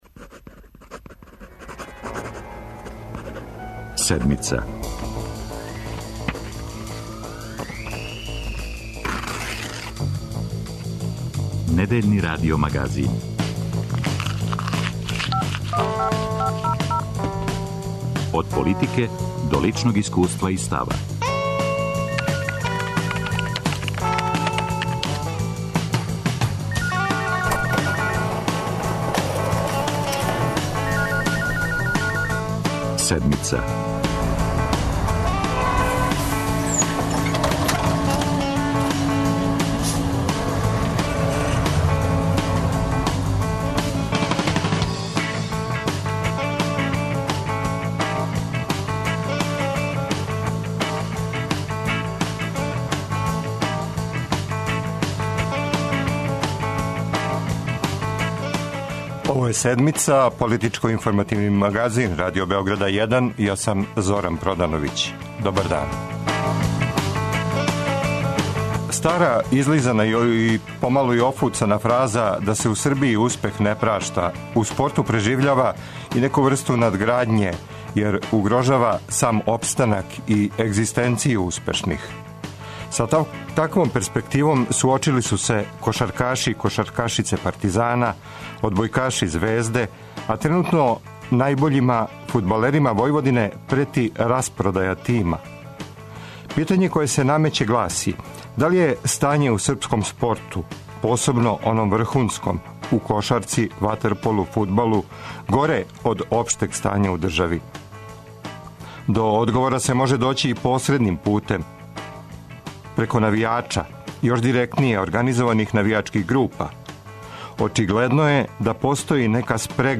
Хоће ли државни новац уништити или унапредити спорт? Гост Седмице је кошаркашки тренер Душко Вујошевић.